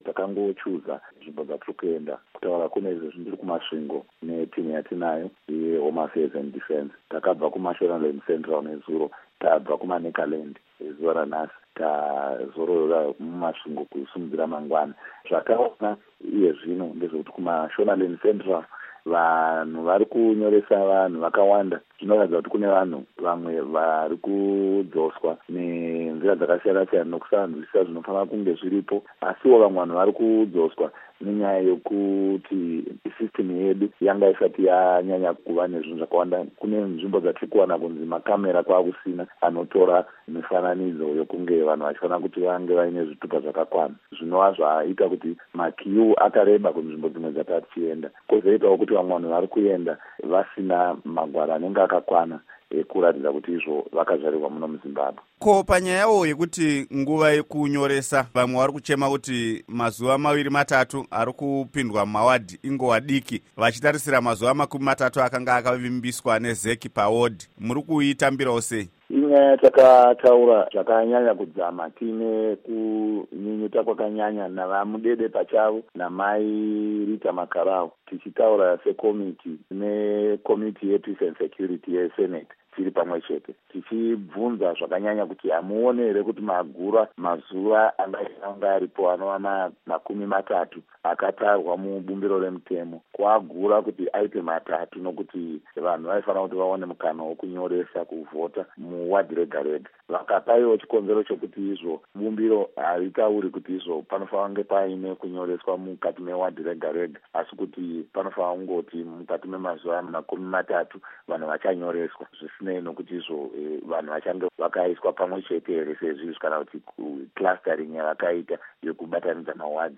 Hurukuro naVaPaul Madzore